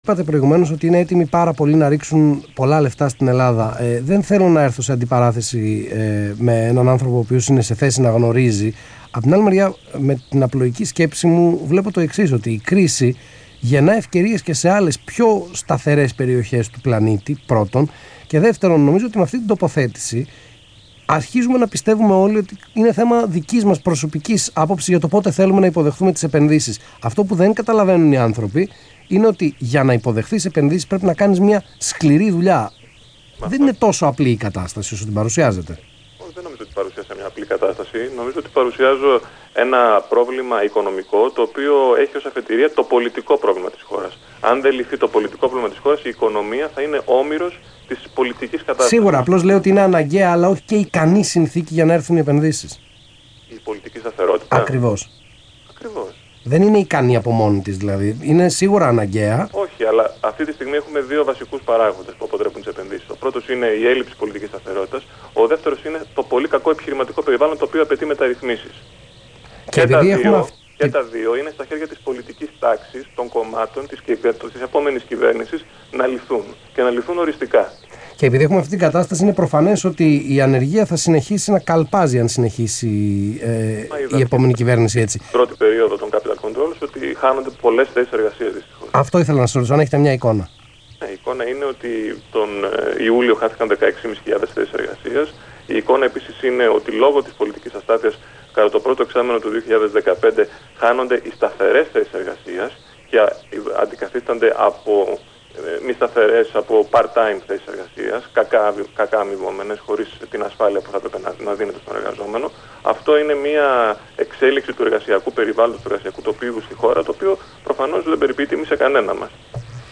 Ο κ. Άκης Σκέρτσος, Γενικός Διευθυντής του ΣΕΒ στον Ρ/Σ Αθήνα 9.84, 9/9/2015